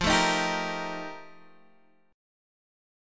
F11 chord {x 8 7 8 6 6} chord